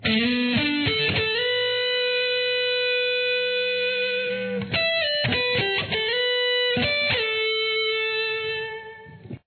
Here is the melody.